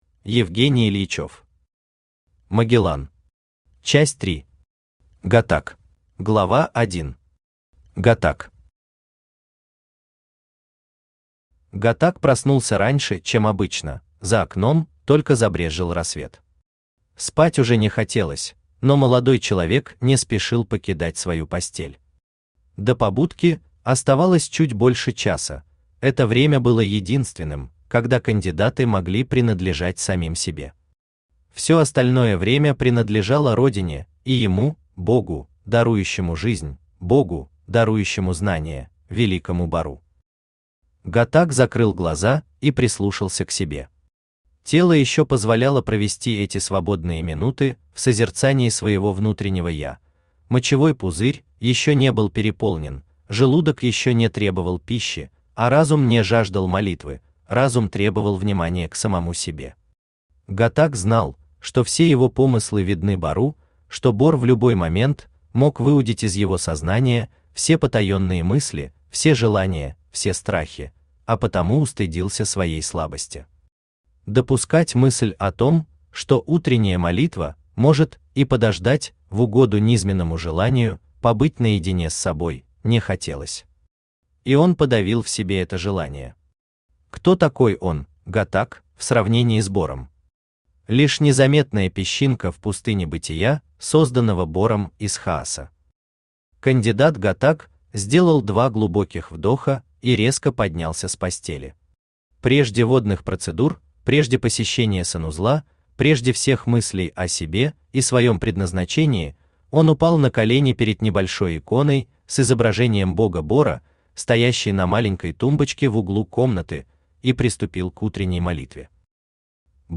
«Гаттак» Автор Евгений Юрьевич Ильичев Читает аудиокнигу Авточтец ЛитРес.